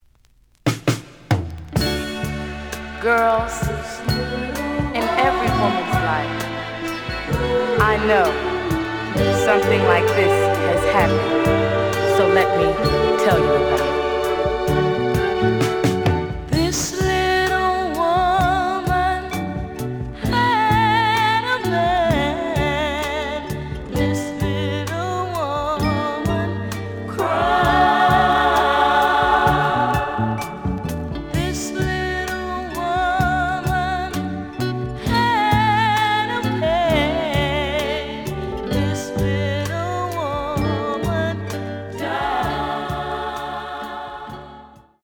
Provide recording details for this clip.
The audio sample is recorded from the actual item. B side plays good.)